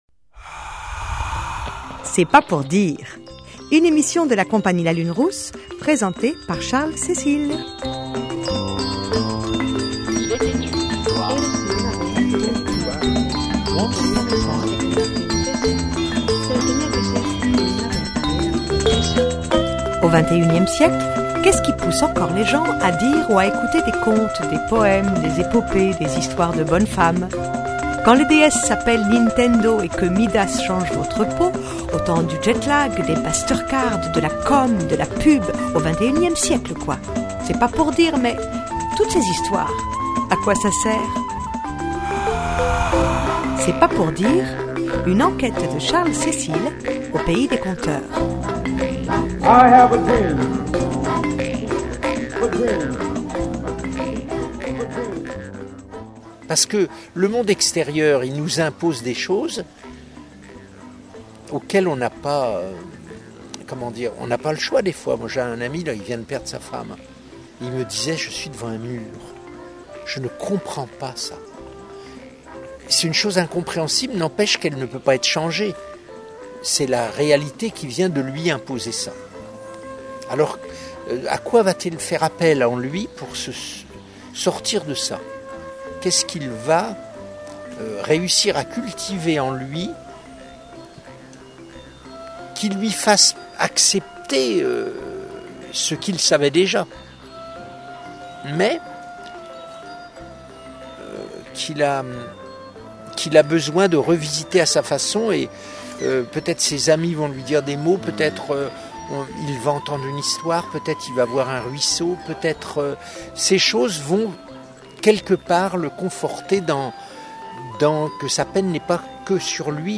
Suite de l’entretien